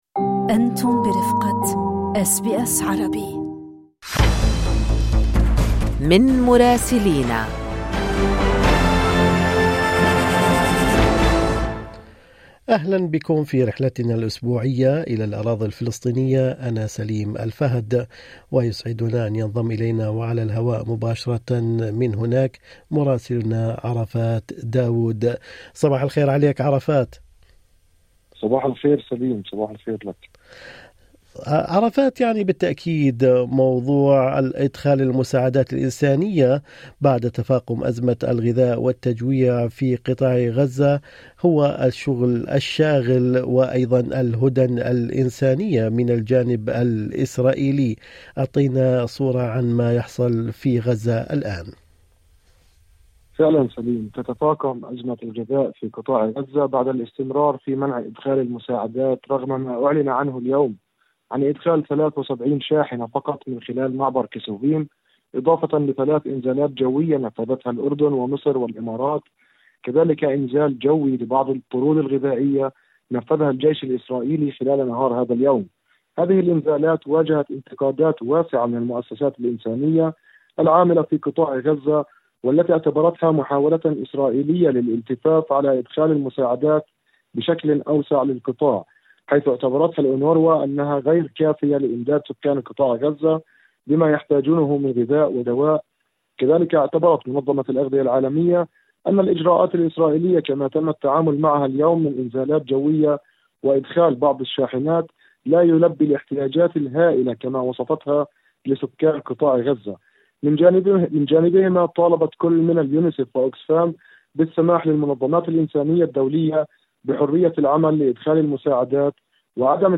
إدخال مساعدات انسانية محدودة إلى غزة ومنظمات الاغاثة تطالب بالمزيد في تقرير مراسل الاراضي الفلسطينية